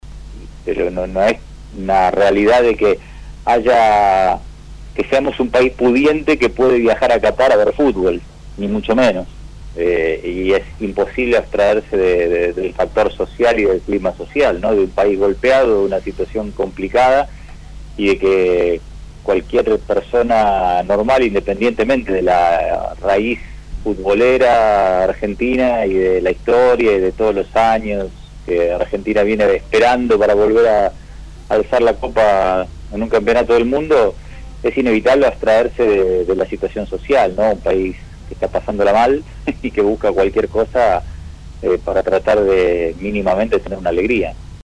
“Es imposible abstraerse del factor social de un país golpeado que supera cualquier pasión futbolista”, expresó el psicólogo en el programa El Mundo del Deporte de Radio LT 17.